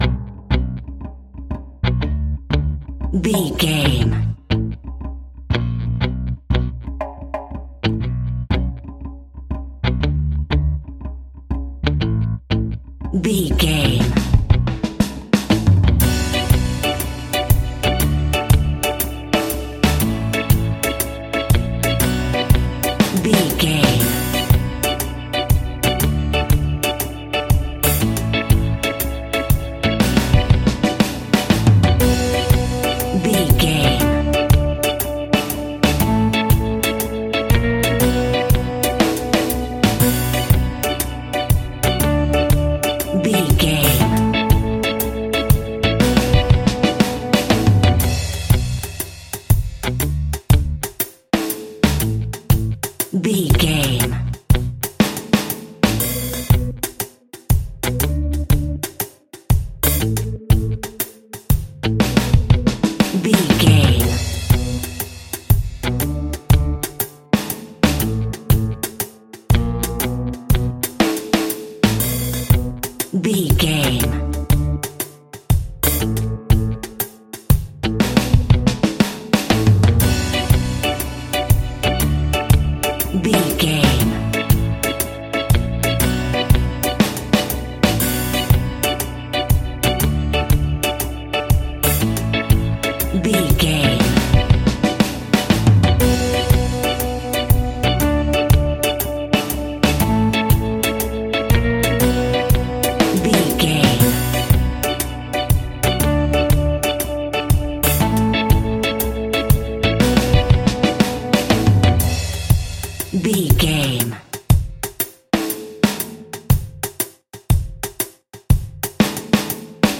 Aeolian/Minor
dub
laid back
chilled
off beat
drums
skank guitar
hammond organ
percussion
horns